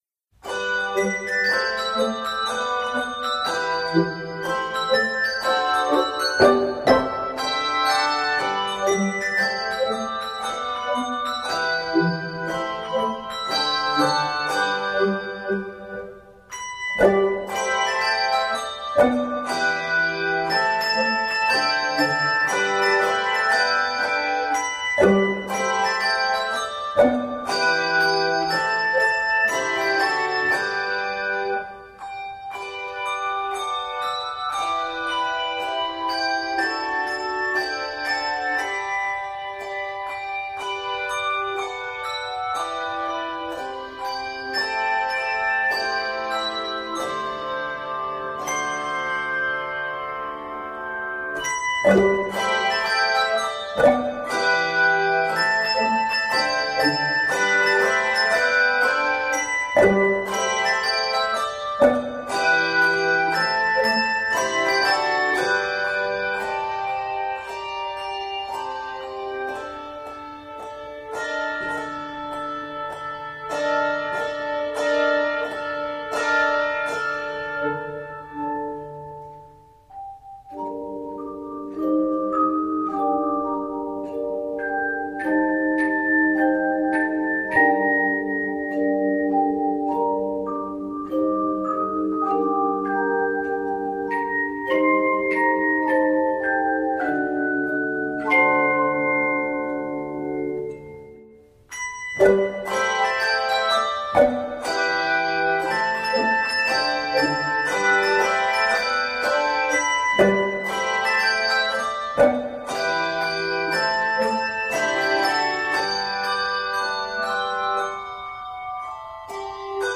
Key of G Major.
Spiritual Arranger